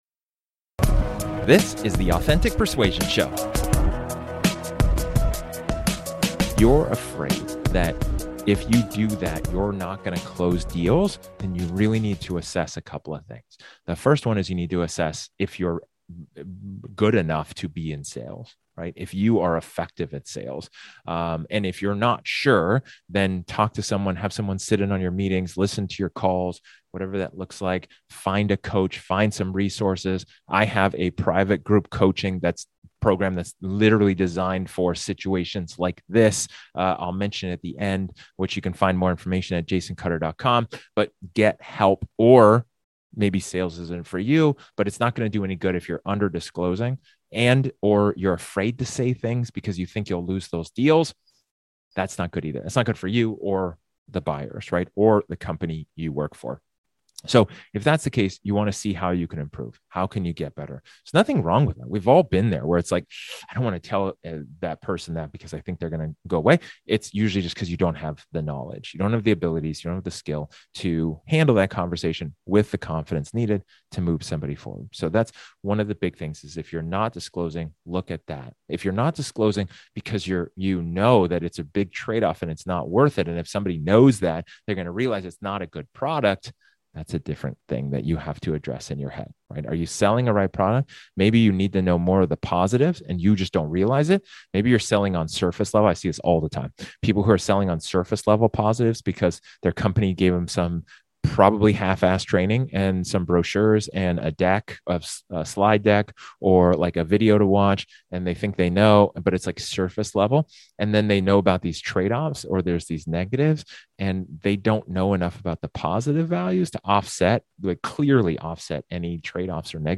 In this solo episode, I talk about empowering improvement.